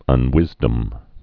(ŭn-wĭzdəm)